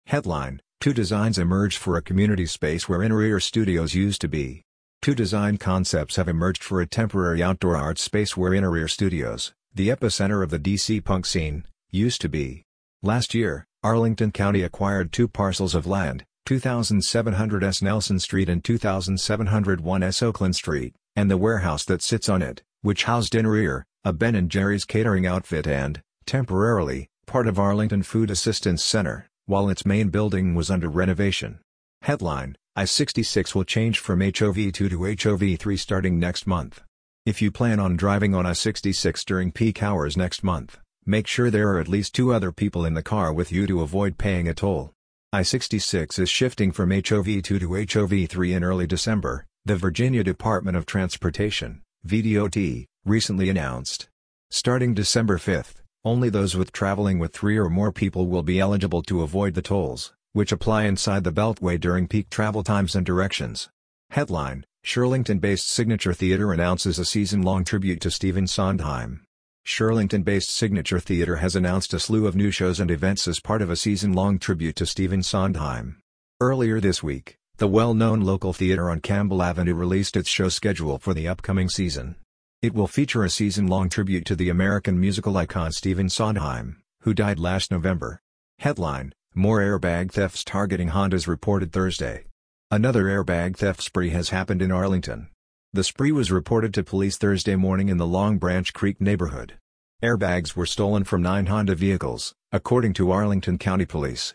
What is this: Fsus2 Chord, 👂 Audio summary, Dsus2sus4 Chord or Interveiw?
👂 Audio summary